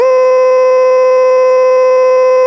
机器人声乐 " 合成歌声音符C 变奏6
Tag: 唱歌 语音 合成器 数字 语音编码器 请注意 puppycat 机器人 电子 合成 自动调谐 C